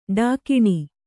♪ ḍākiṇi